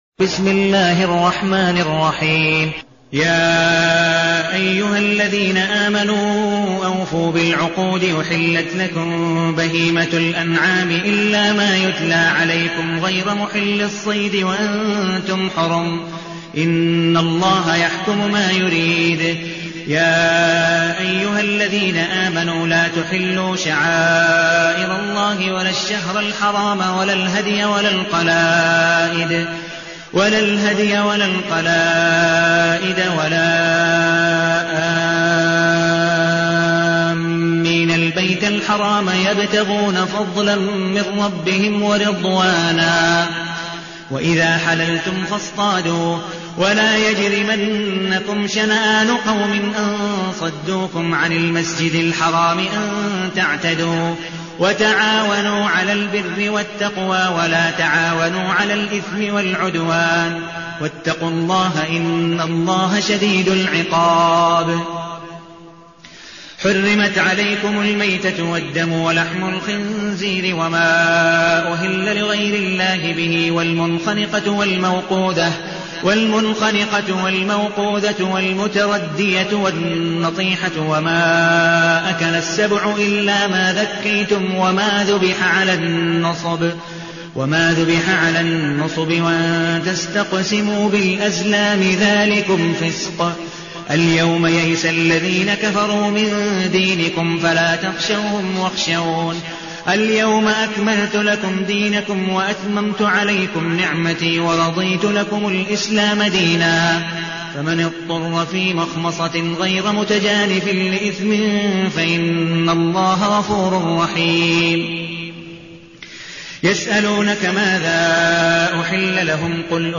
المكان: المسجد النبوي الشيخ: عبدالودود بن مقبول حنيف عبدالودود بن مقبول حنيف المائدة